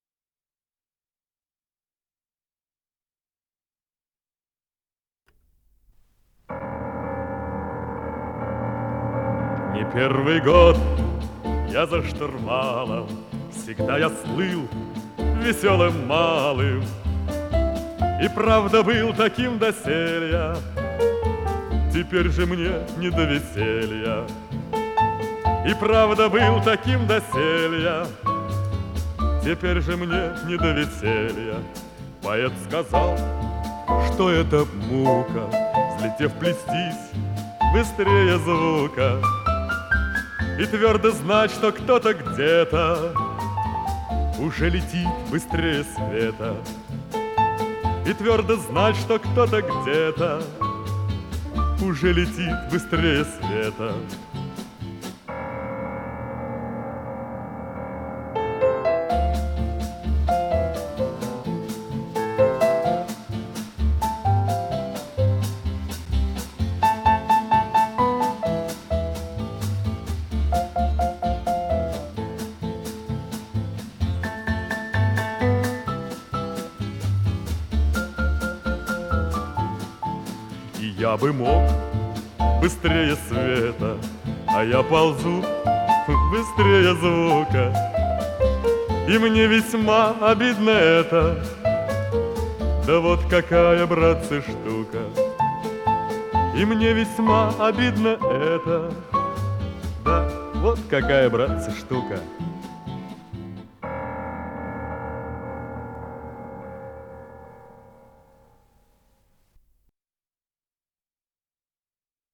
с профессиональной магнитной ленты
АккомпаниментИнструментальный ансамбль